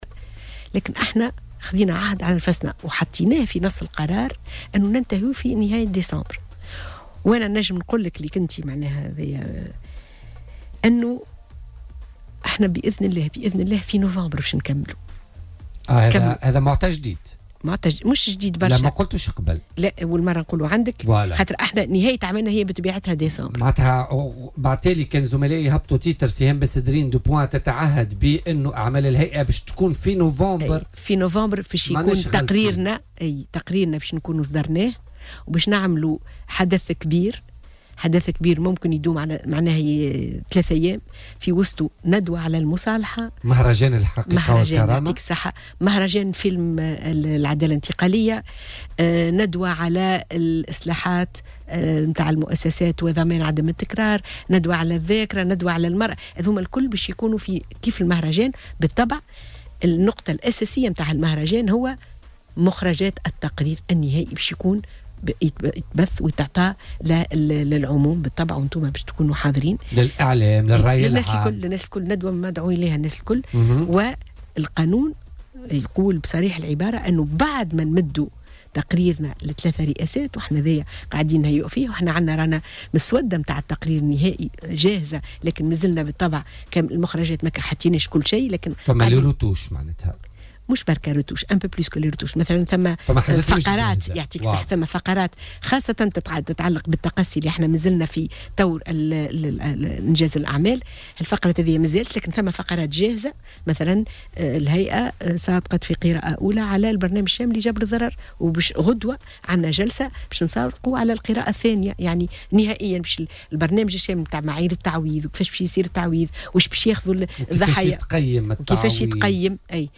وأوضحت ضيفة "بوليتيكا" في ستوديو "الجوهرة اف أم" بتونس أن عمل الهيئة ينتهي في نوفمبر باصدار تقرير الهيئة وتنظيم حدث كبير وهو مهرجان هيئة الحقيقة والكرامة بمناسبة انهاء أعمال الهيئة.